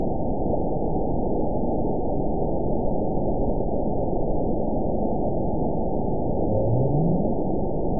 event 922154 date 12/27/24 time 18:59:22 GMT (11 months, 1 week ago) score 9.48 location TSS-AB04 detected by nrw target species NRW annotations +NRW Spectrogram: Frequency (kHz) vs. Time (s) audio not available .wav